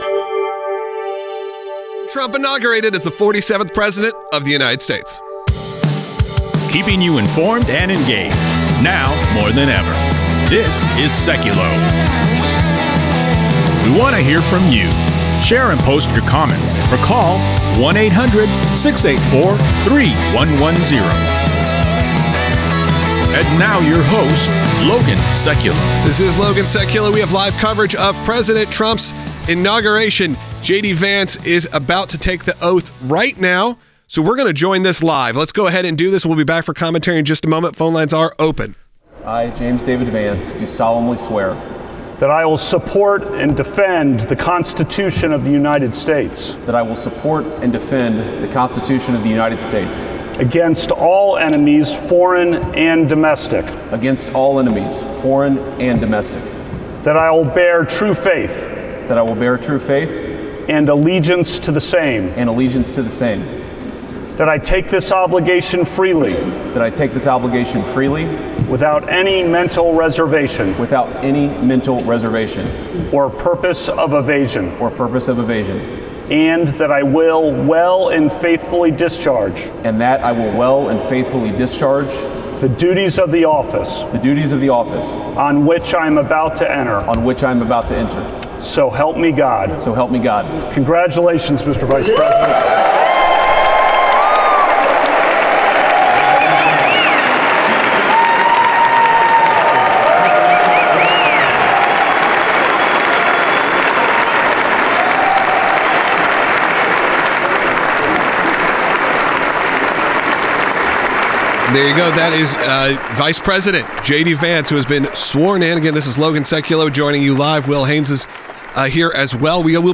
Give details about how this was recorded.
LIVE: Donald Trump Presidential Inauguration